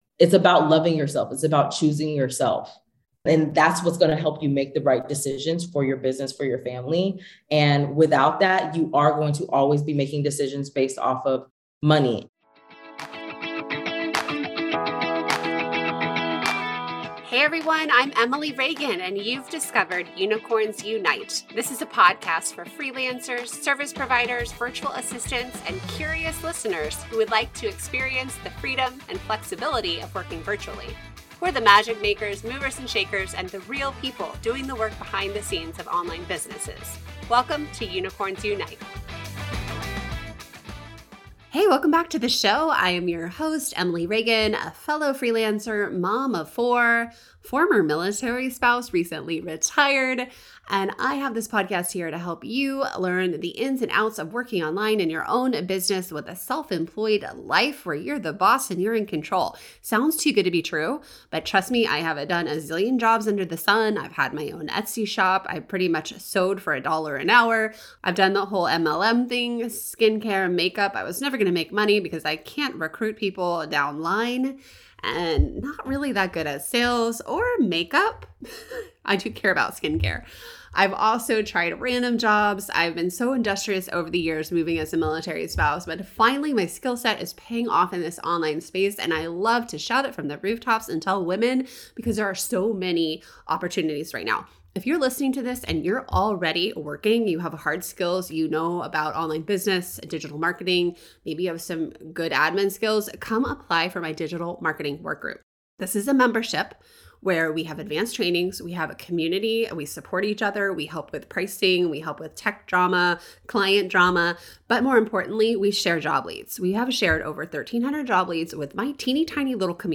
This interview is chock-full of goodness; Do’s and don’ts of ads management… including the one thing you should NEVER EVER say to your clients.